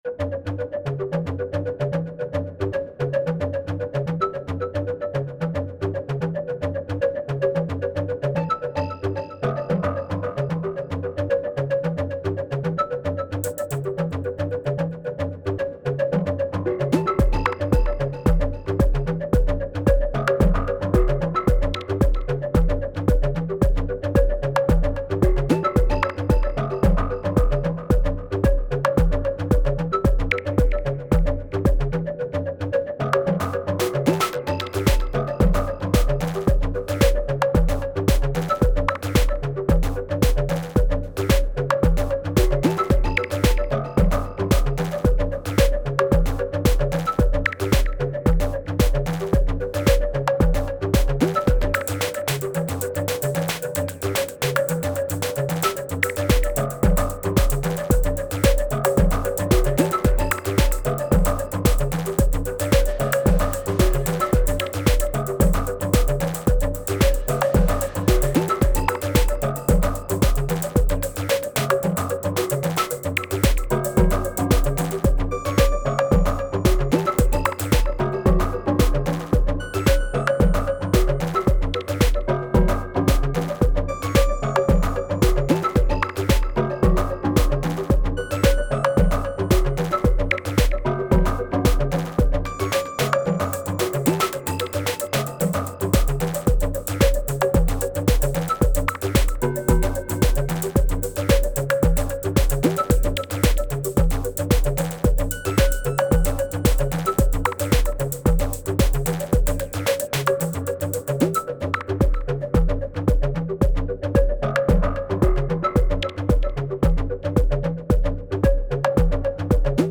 :smiling_face: It does a nice flute-ish sound, cool atonal bell-ish stuff, your basic percussion stuff. For melodic stuff you can sort of change the waveform a bit by pulling up the MENV a lot and keep the feedback completely down. 7x SD Basic